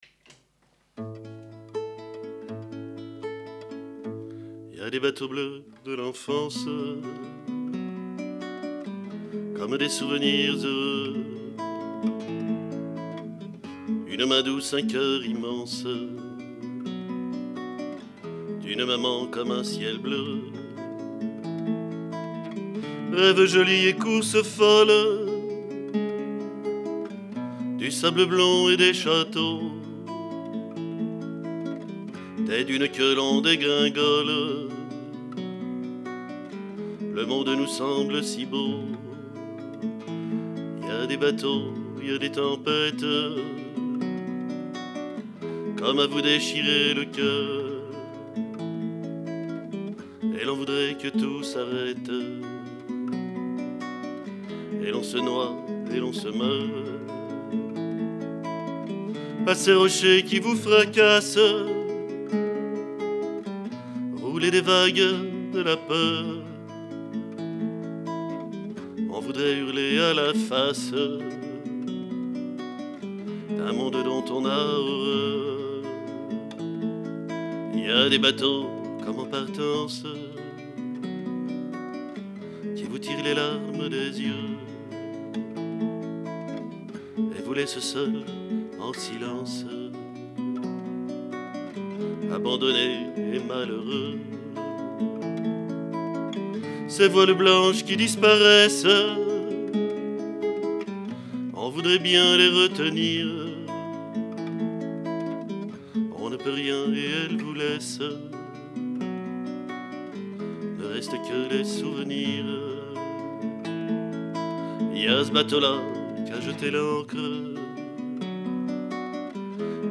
chant, guitare